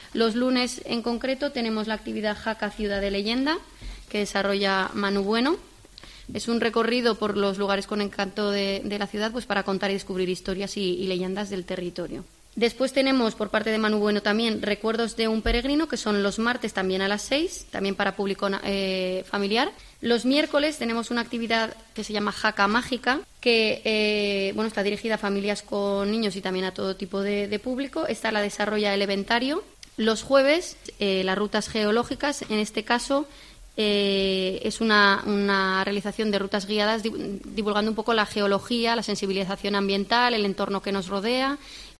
Lucía Guillén (concejal de Turismo) explica las novedades